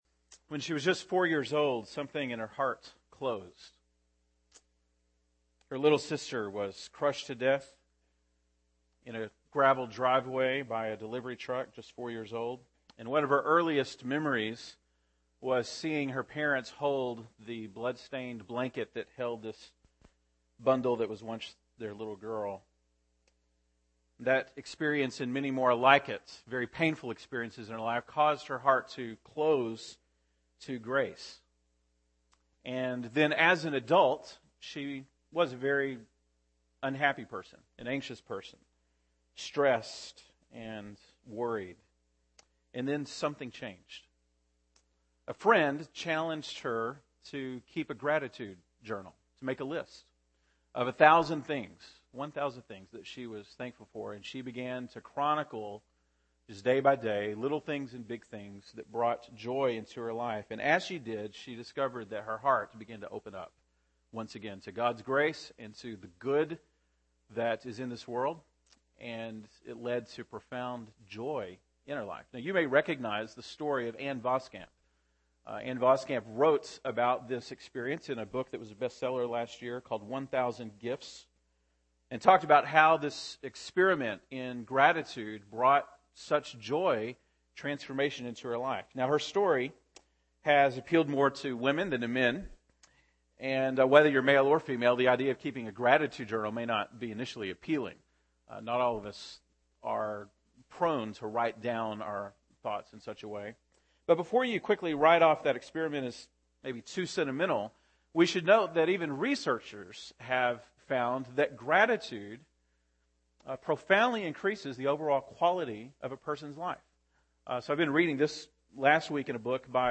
November 25, 2012 (Sunday Morning)